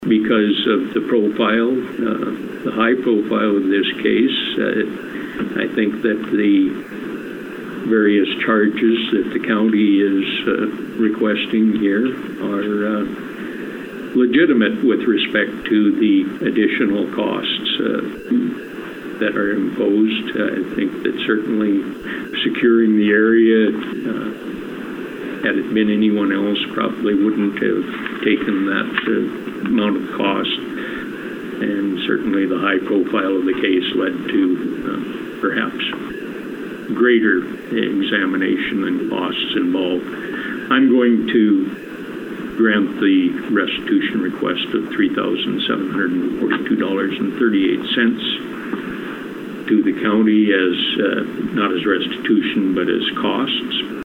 Judge Brown announces order to pay Hyde County’s costs